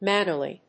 発音記号
• / ˈmænərli(カナダ英語)